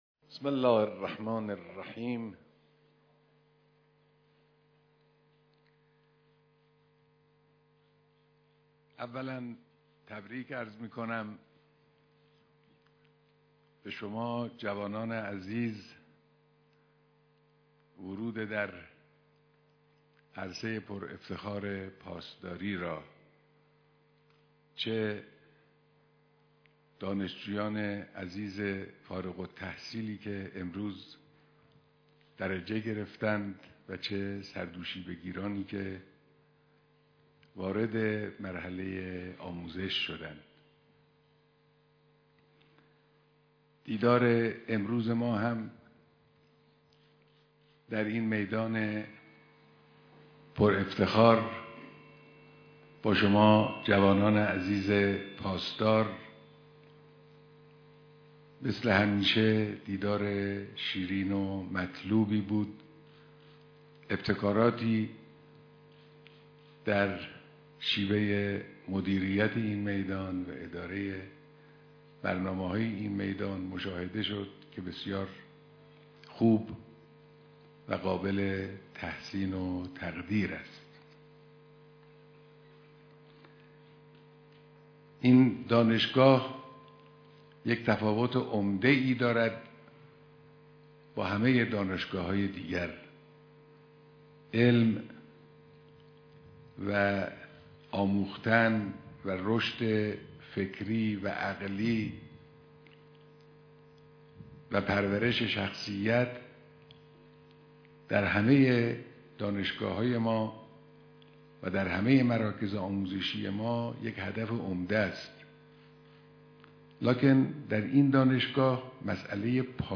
سخنرانی رهبری